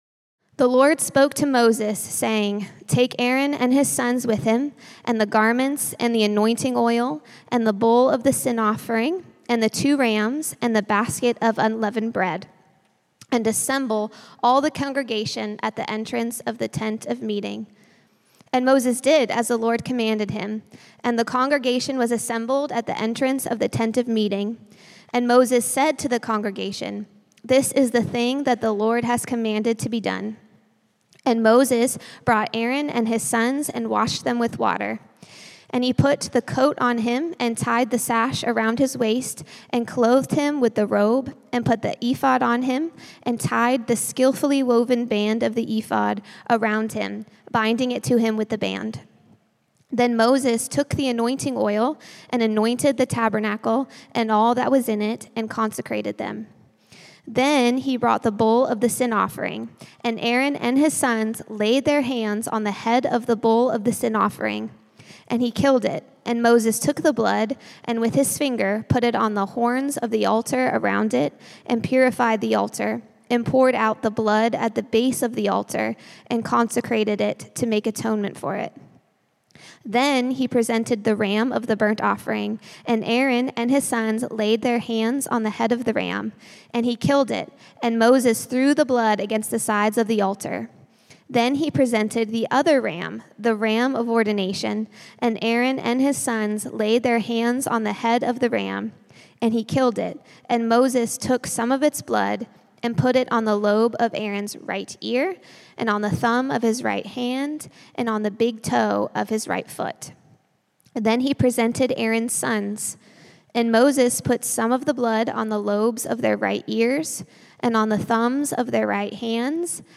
A message from the series "Holy God Holy People."